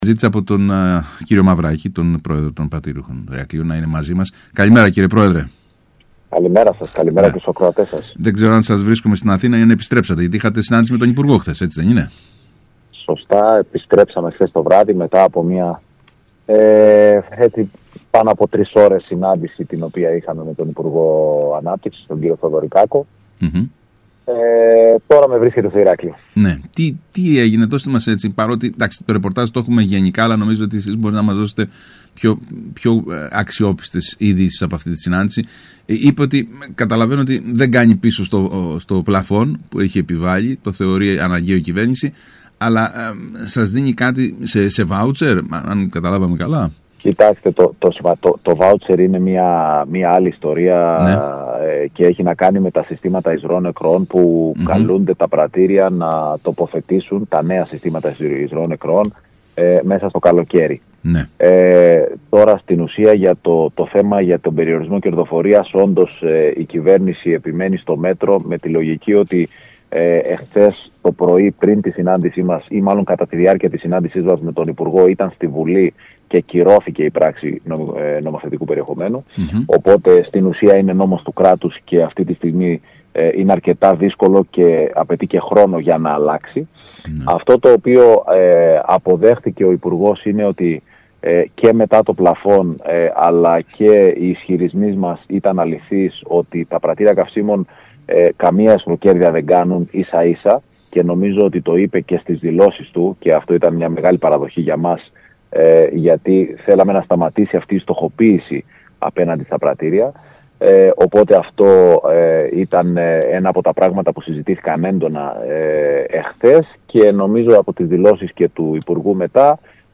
μιλώντας στον ΣΚΑΪ Κρήτης 92,1